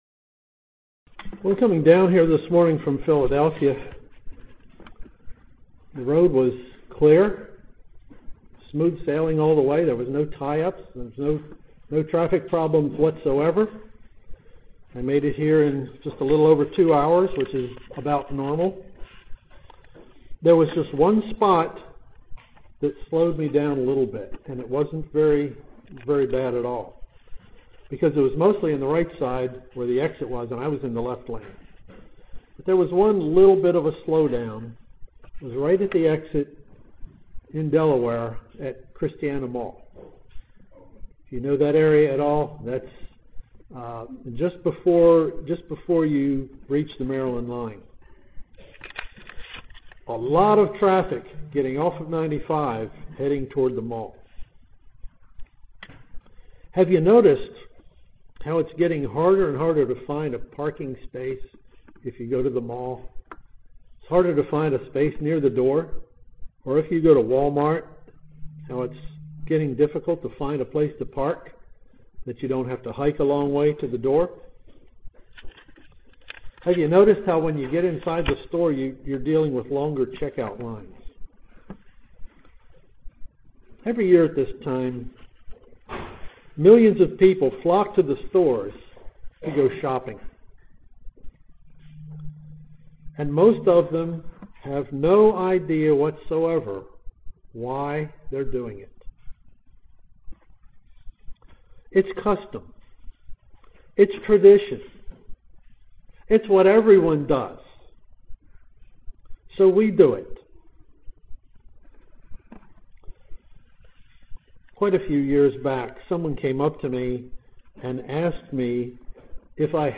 Given in Columbia, MD
UCG Sermon Studying the bible?